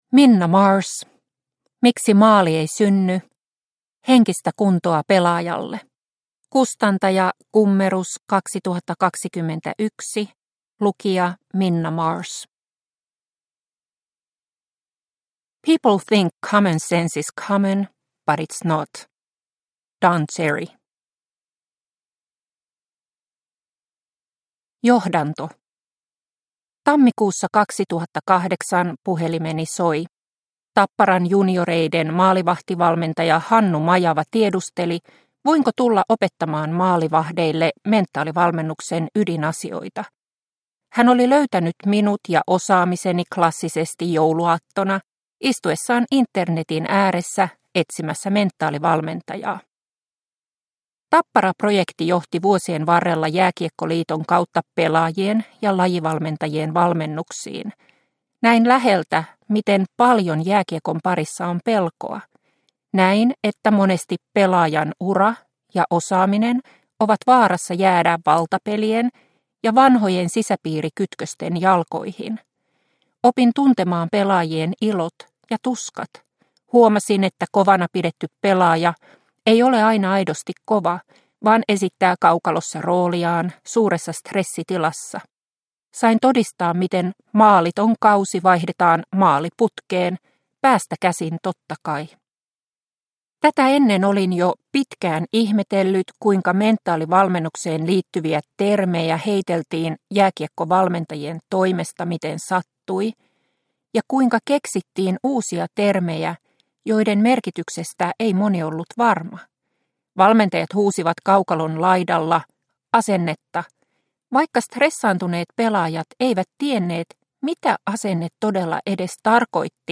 Miksi maali ei synny? – Ljudbok – Laddas ner